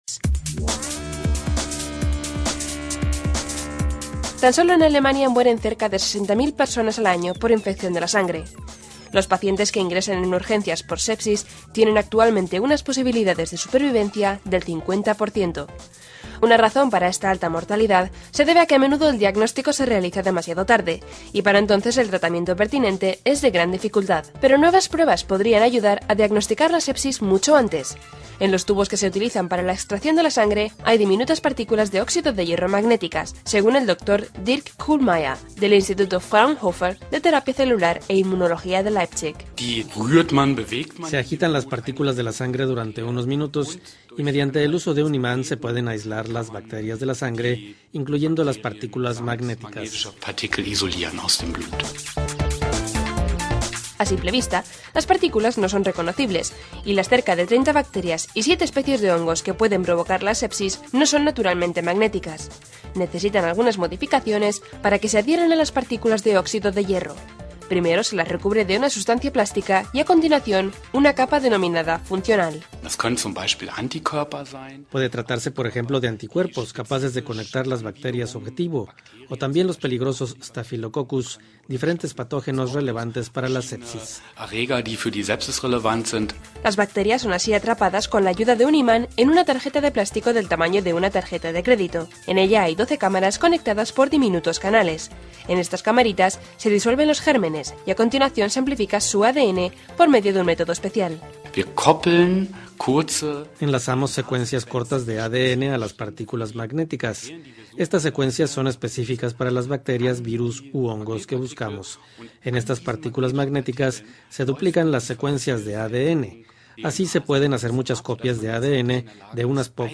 En Alemania, investigadores desarrollan pruebas capaces de localizar los gérmenes que causan la enfermedad de la sepsis. Escuche el informe de la Deutsche Welle.